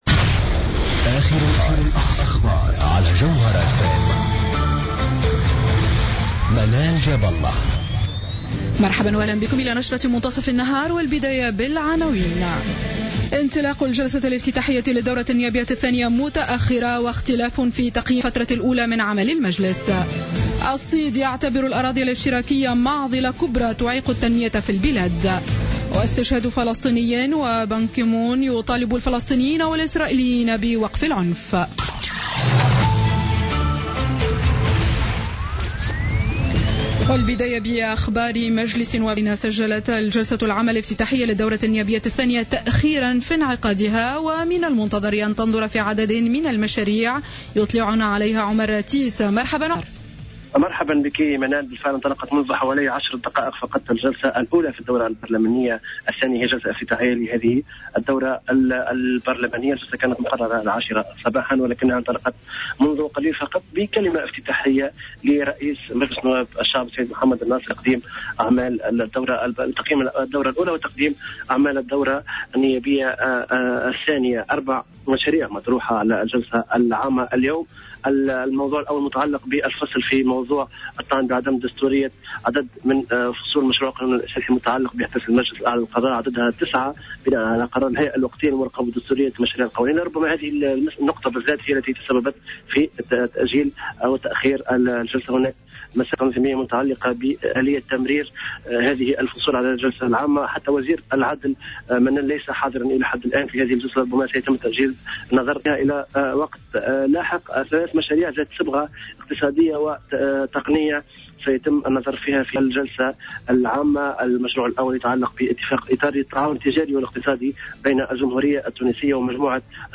نشرة أخبار منتصف النهار ليوم الثلاثاء 20 أكتوبر 2015